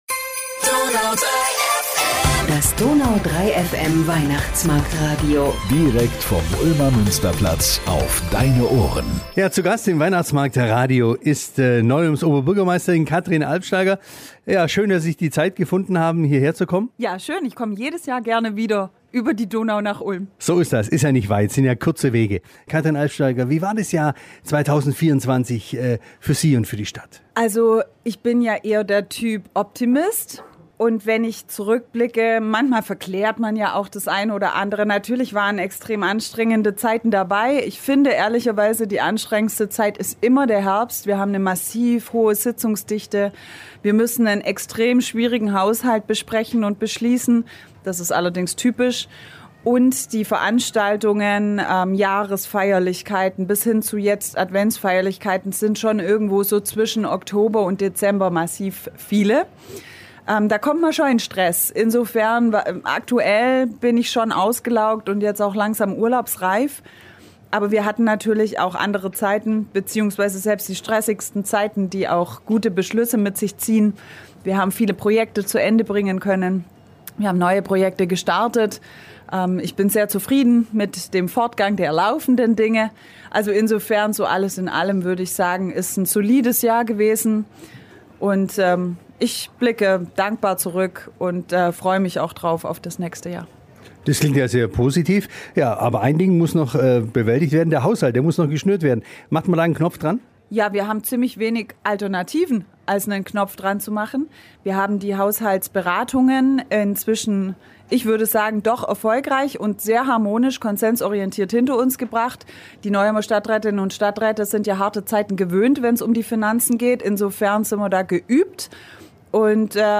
Zu Gast im WMR auf dem Münsterplatz war auch Neu-Ulms Oberbürgermeisterin Katrin Albsteiger.